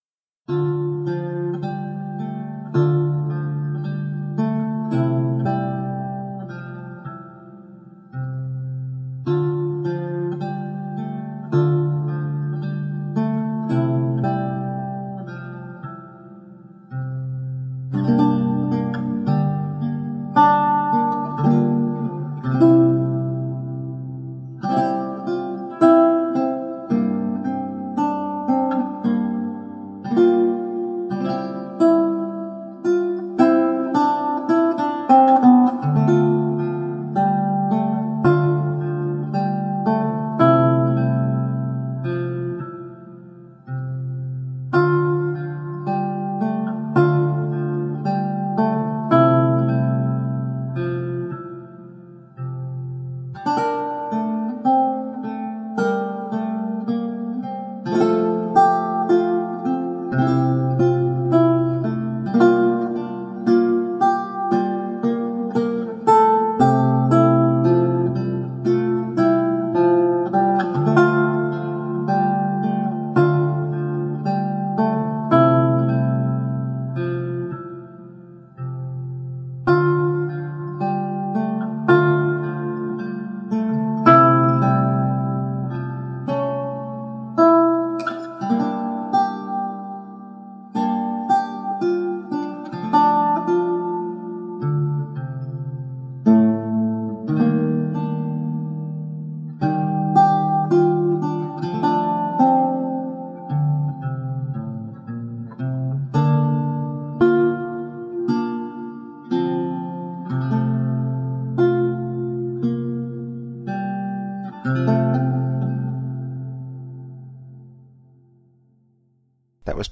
Original and evocative lute music.